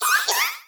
Cri de Larméléon dans Pokémon Épée et Bouclier.